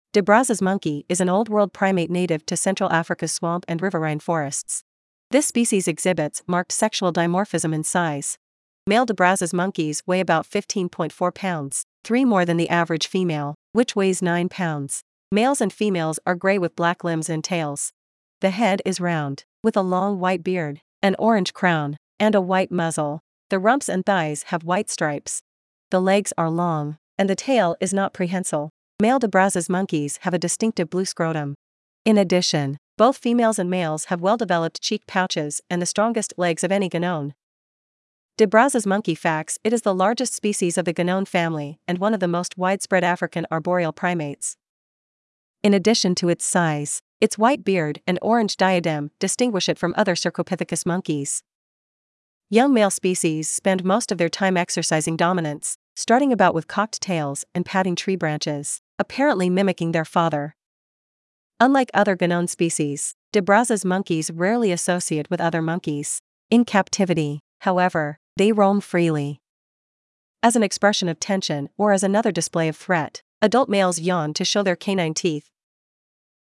De Brazza’s Monkey
De-Brazzas-Monkey.mp3